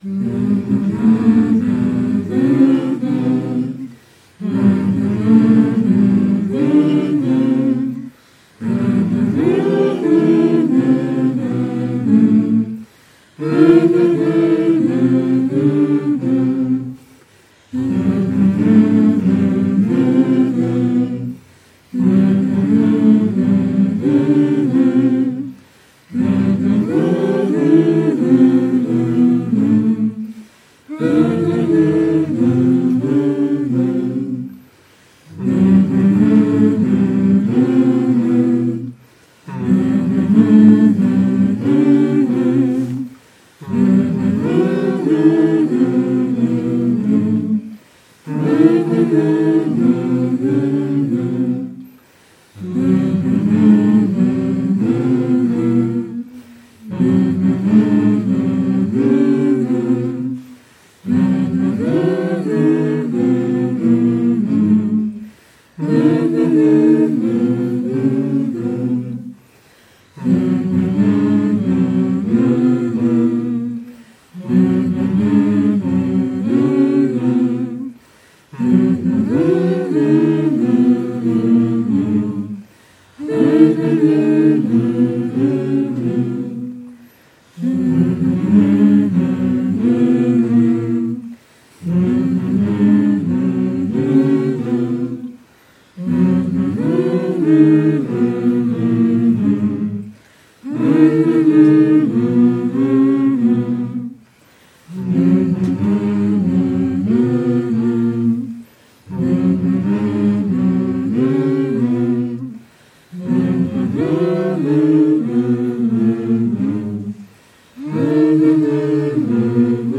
Choir Recording Info
The song should be hummed in loop without interruption or break between each loop. You will be divided in four different vocal range groups: soprano alto tenor bass. Each group will have a microphone set-up for recording.
Little by little the choir dwindles until the last person leaves the recording room.
You have to know that the recording of this performance will be used for a 4-channel sound installation project.